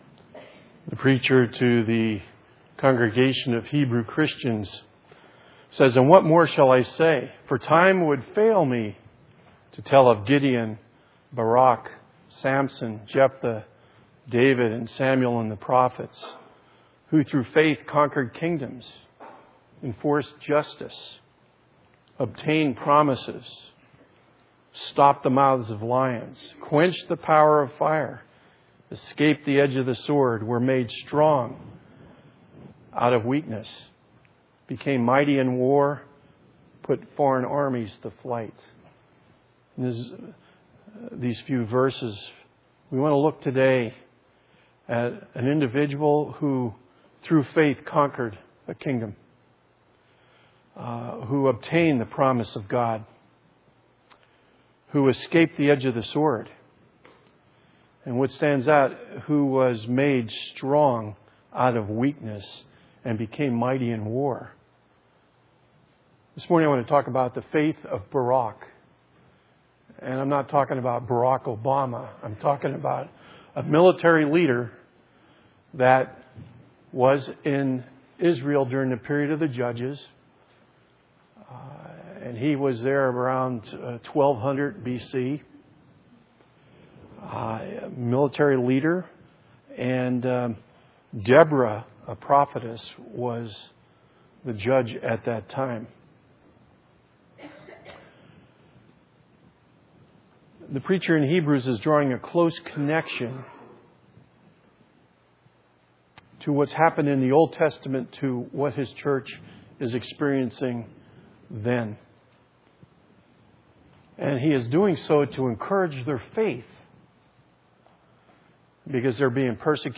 A Collection of 2016 Sermons from Windsor Baptist Chruch June 19, 2016 - Does not the Lord Go out Before You?